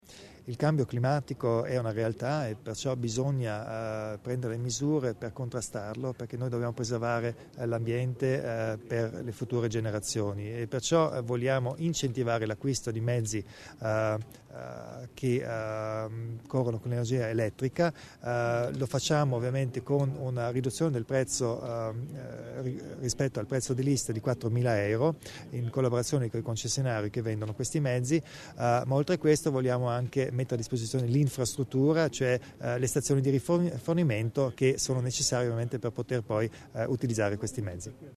L'Assessore Theiner spiega l'importanza del concetto di green region